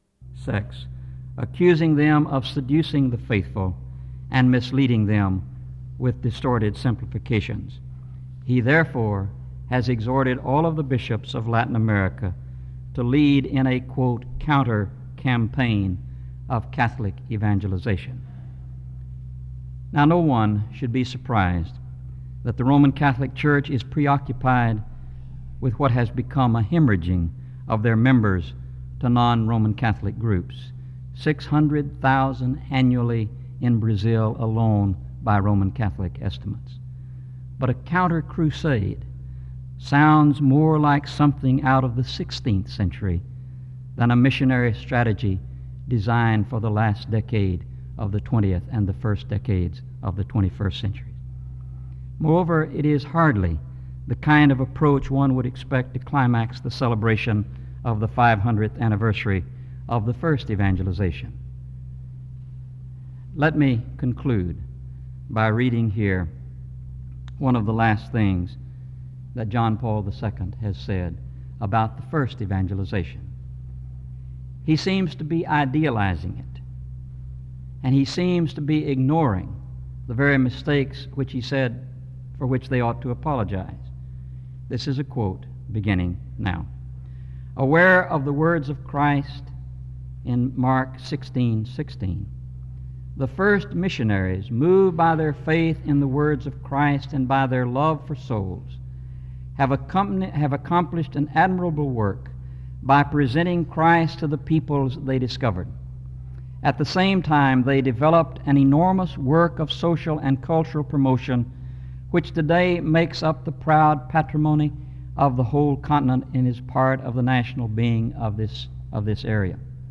In Collection: SEBTS Chapel and Special Event Recordings